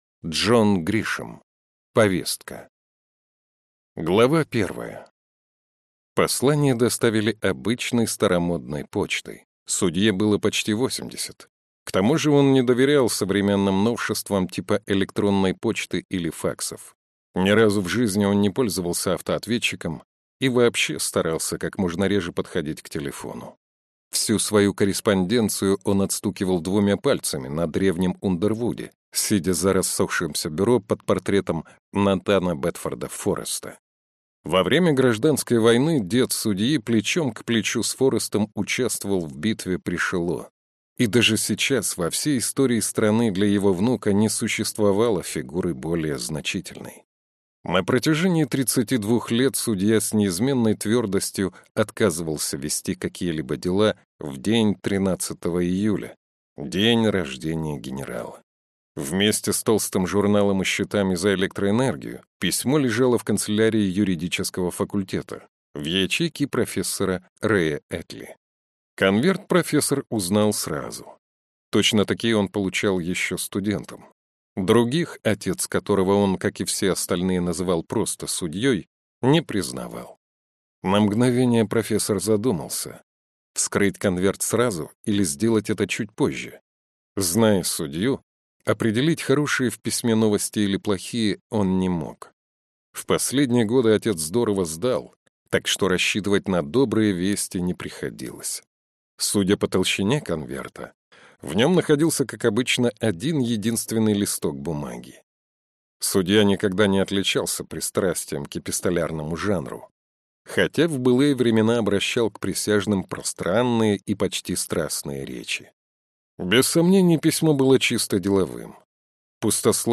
Аудиокнига Повестка | Библиотека аудиокниг